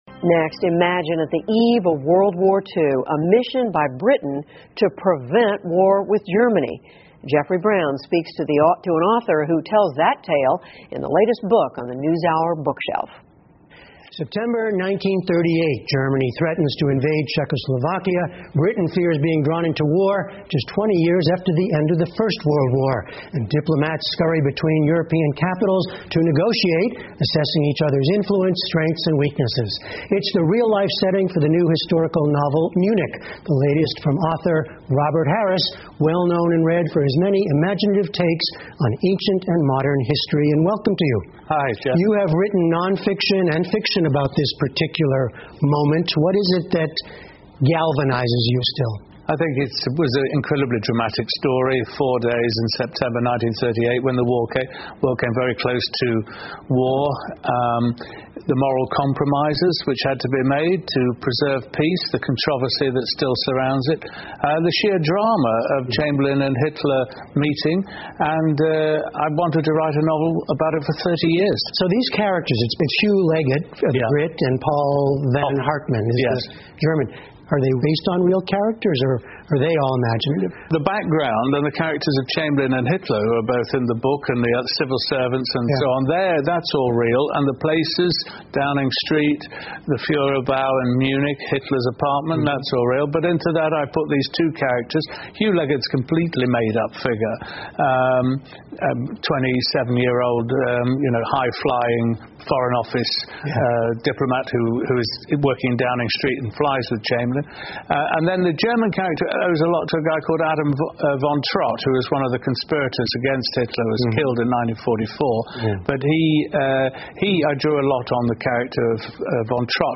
PBS高端访谈:小说家罗伯特·哈里斯重塑二战边缘的英国历史 听力文件下载—在线英语听力室